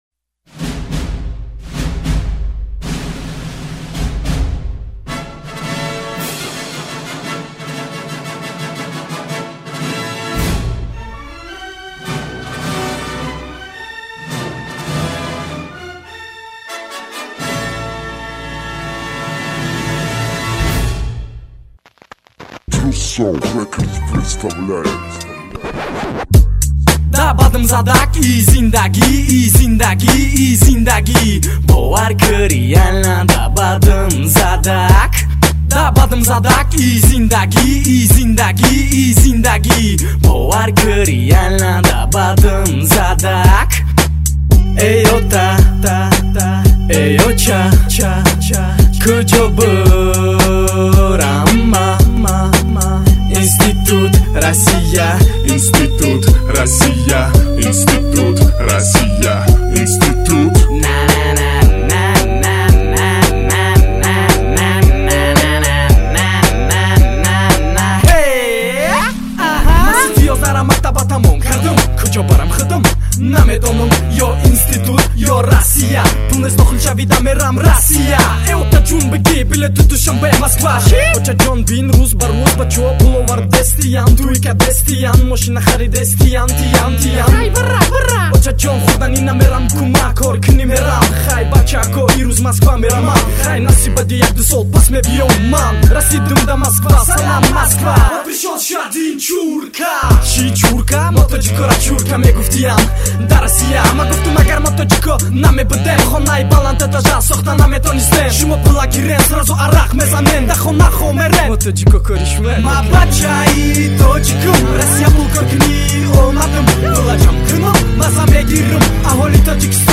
Категория: Тадж. Rap